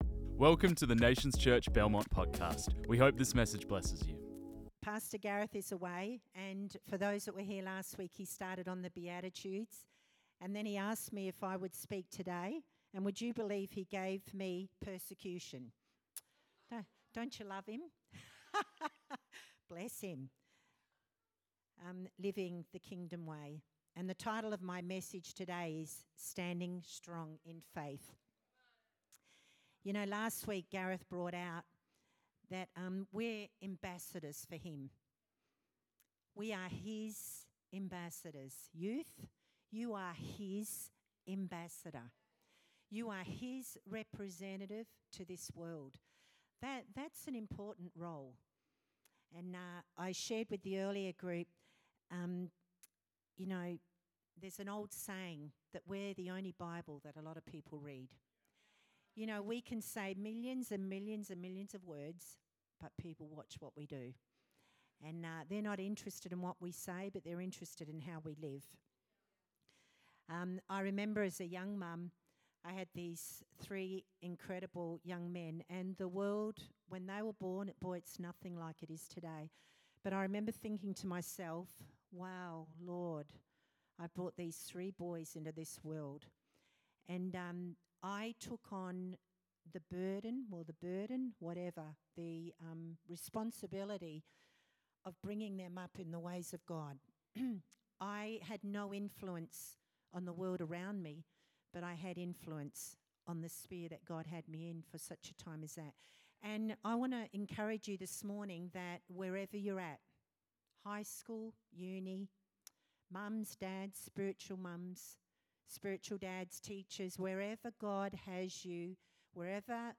This message was preached on 26 January 2024.